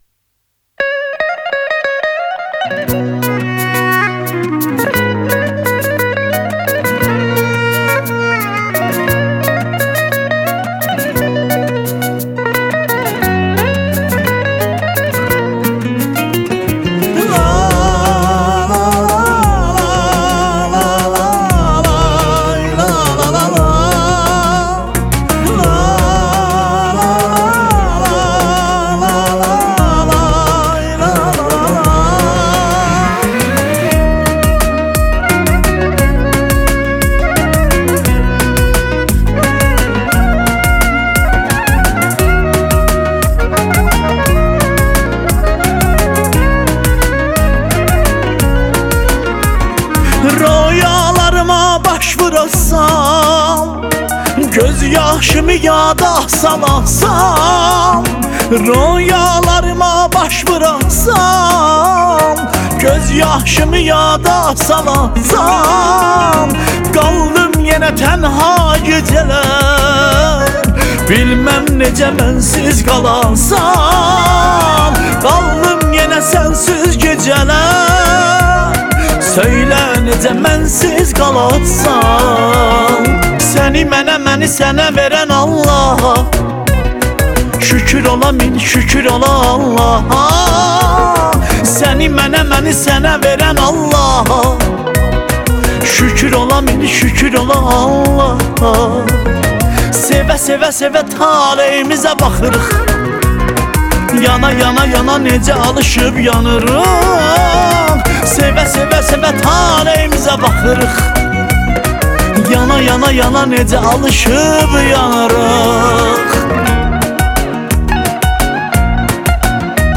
موسیقی آذری
یک قطعه موسیقی زیبای آذری